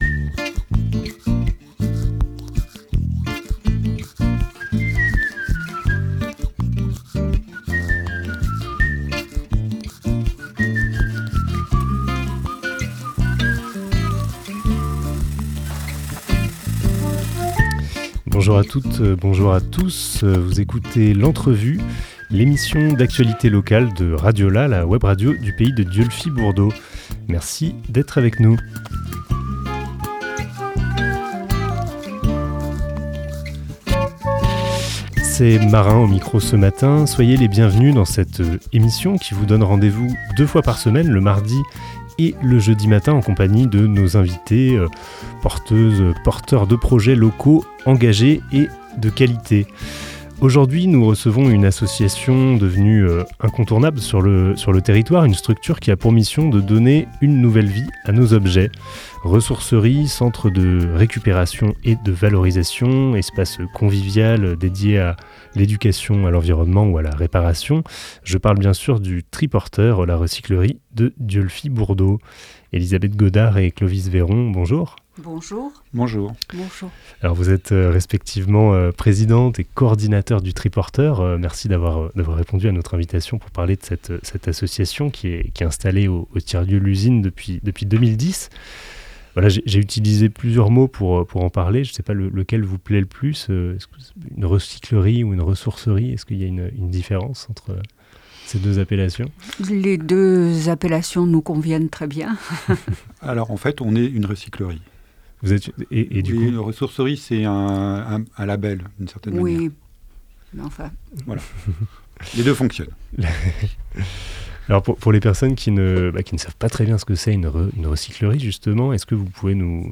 12 décembre 2023 12:07 | Interview